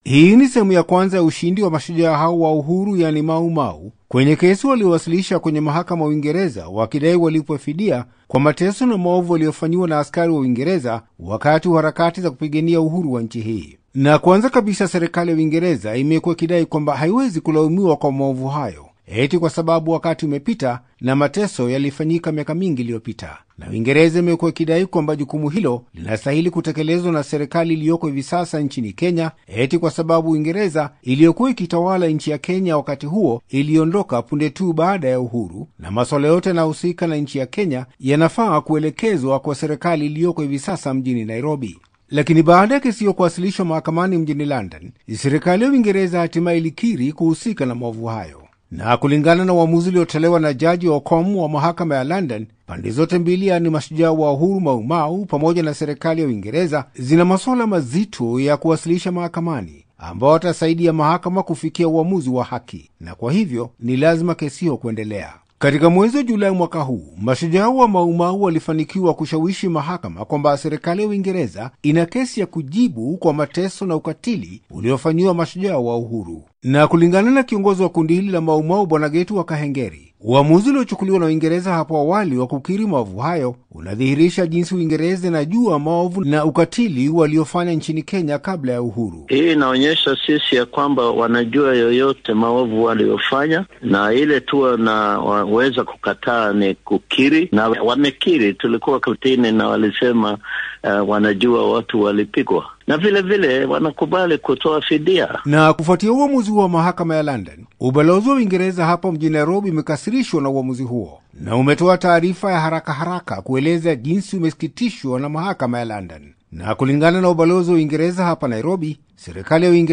Ripoti